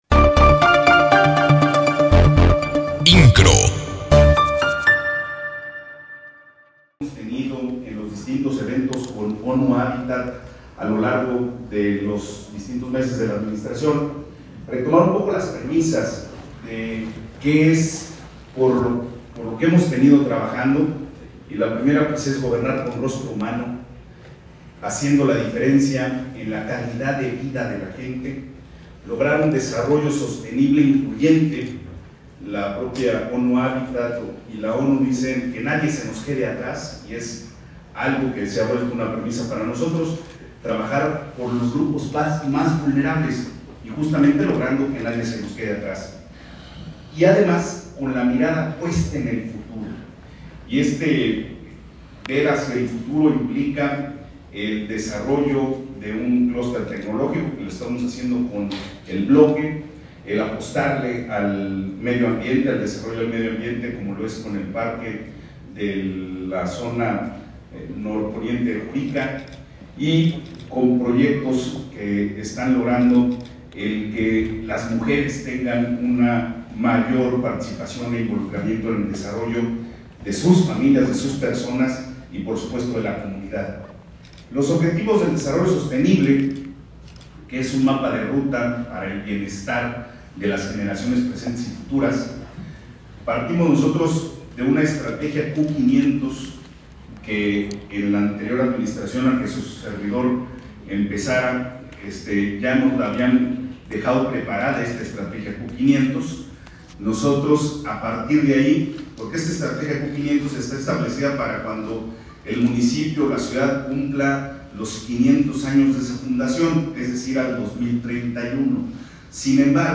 Presente en la conferencia semanal del Gobernador Mauricio Kuri, esta mañana el Presidente Municipal de Querétaro Luis Nava, presenta el panorama que empalma las acciones de su administración con los objetivos del gobierno del estado.